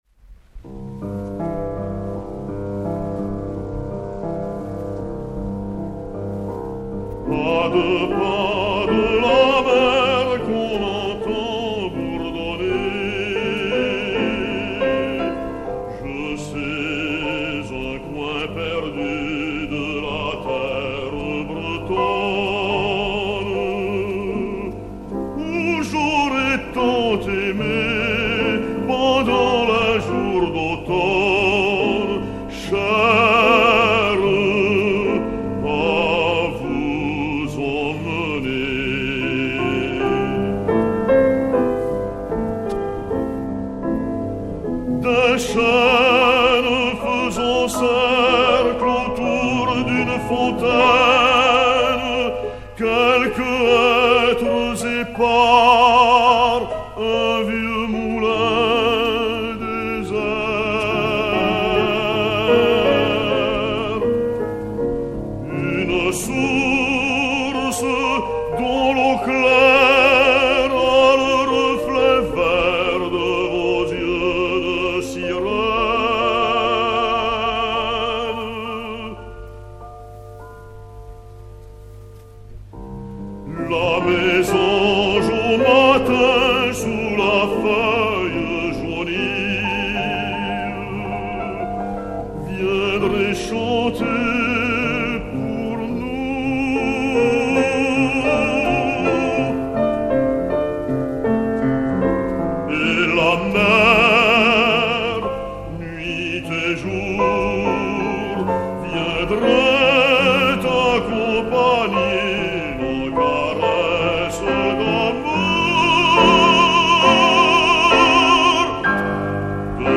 baryton-basse français
au piano